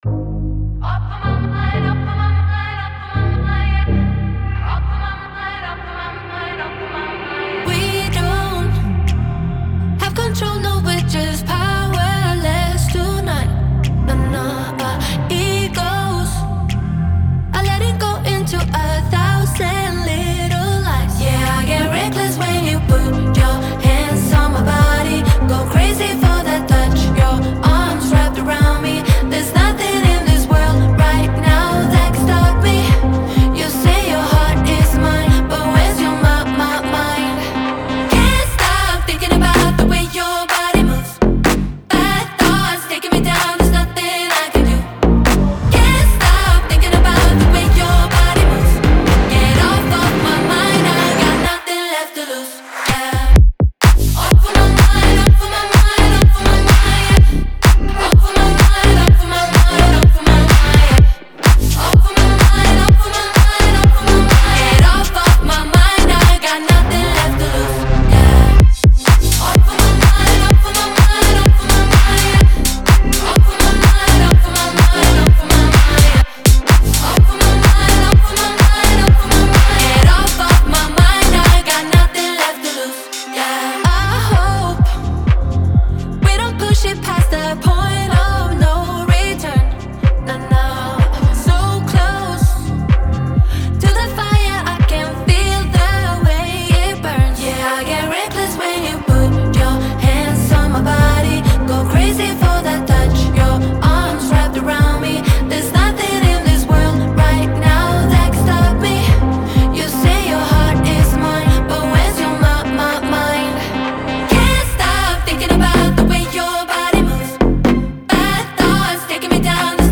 энергичная поп-трек